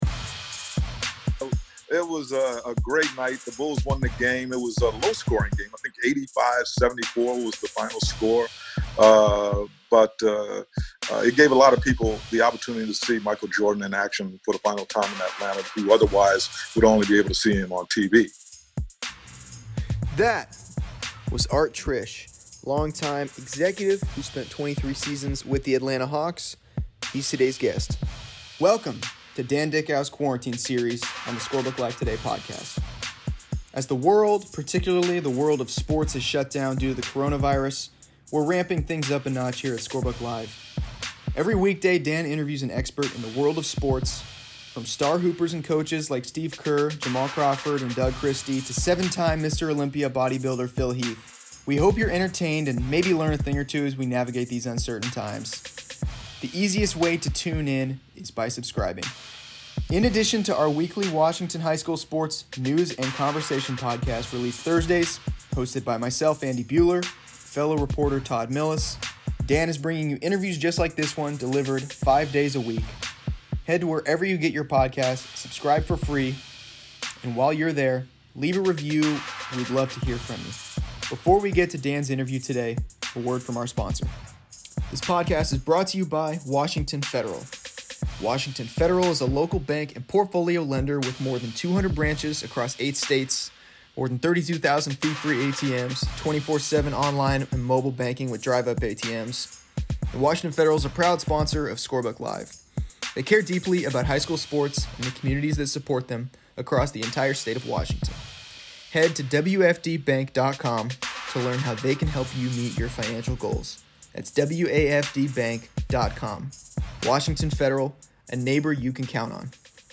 Welcome to analyst Dan Dickau's Quarantine Series. High school sports have been canceled for the remainder of the school year, but the Scorebook Live Today podcast is ramping things up amid the COVID-19 outbreak. Each weekday, Dickau releases an interview with a wide range of experts in the world of sports.